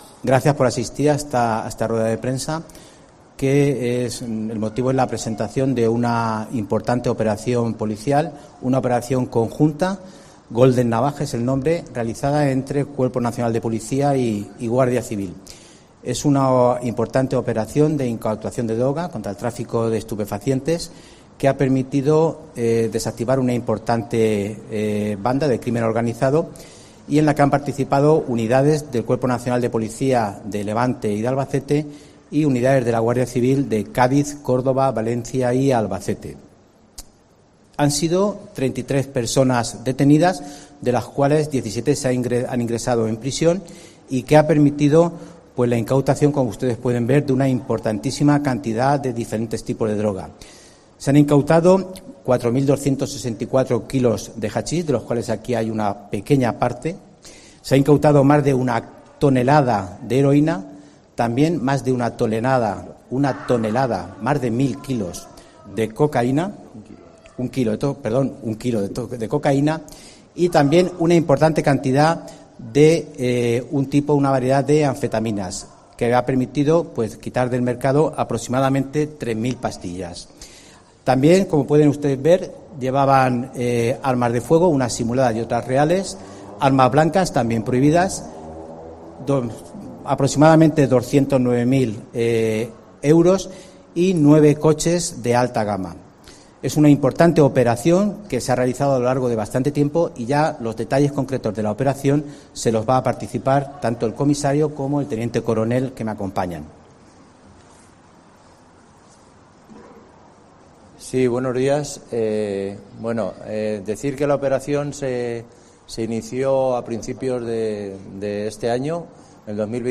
Operación Golden. Rueda de prensa